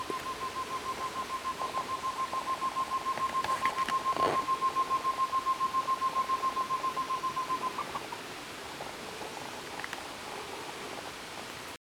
Tovaca-campainha (Chamaeza campanisona)
Nome em Inglês: Short-tailed Antthrush
Localidade ou área protegida: Parque Provincial Salto Encantado
Condição: Selvagem
Certeza: Gravado Vocal